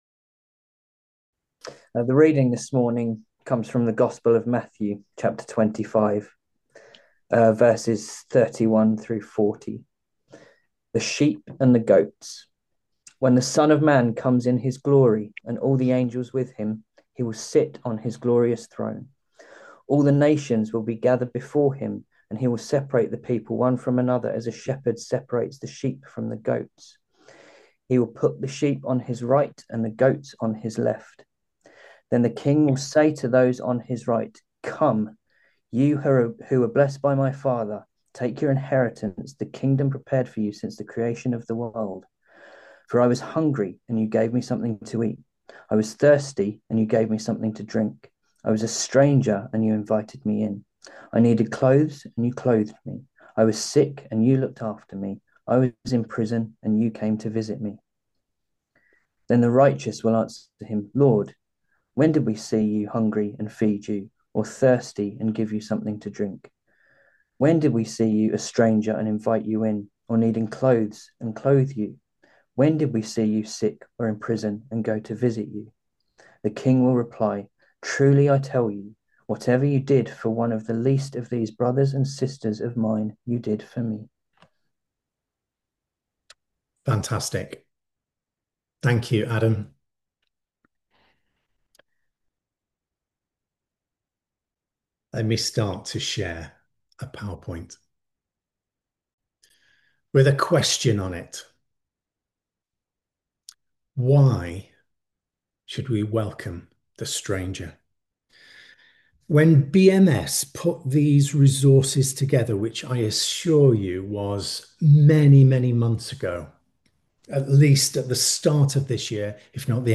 Harvest Service, Welcome the Stranger